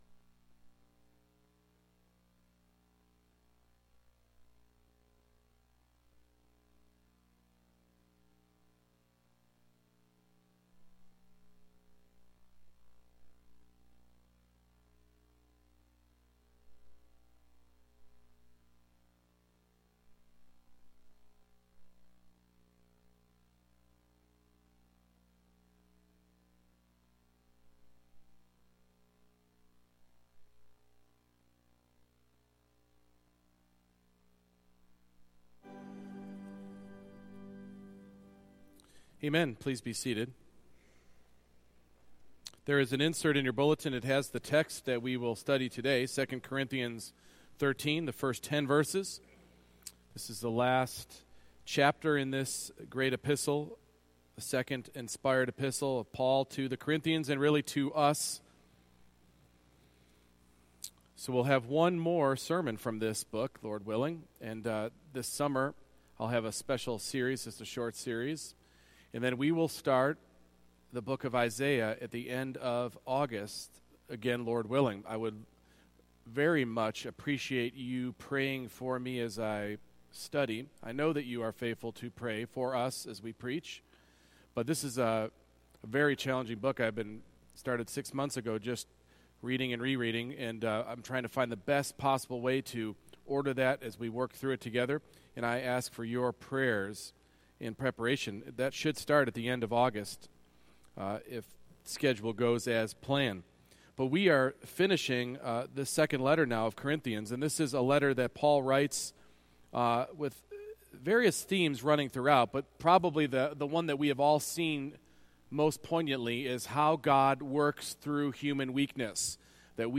2 Corinthians 13:1-10 Service Type: Morning Worship The Church is a community of redeemed sinners accountable to Jesus who actively shepherds us by His Word.